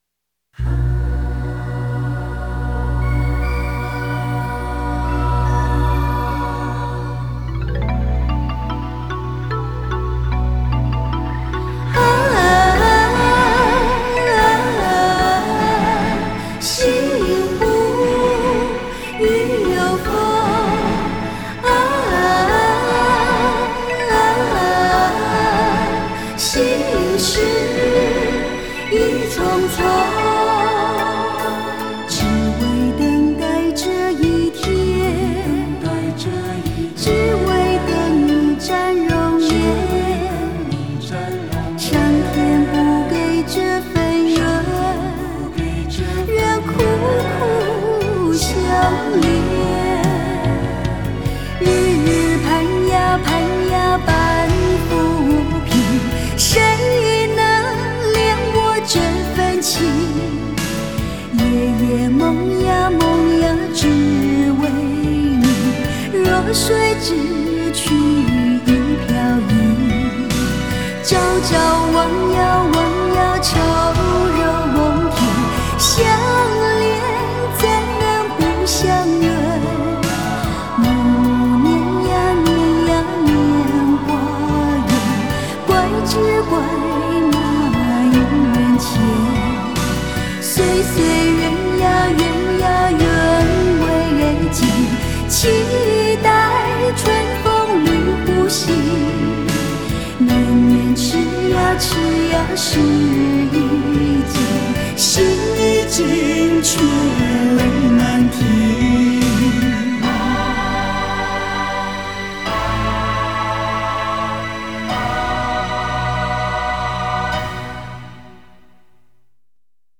国风 收藏 下载